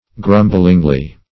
grumblingly - definition of grumblingly - synonyms, pronunciation, spelling from Free Dictionary Search Result for " grumblingly" : The Collaborative International Dictionary of English v.0.48: Grumblingly \Grum"bling*ly\, adv.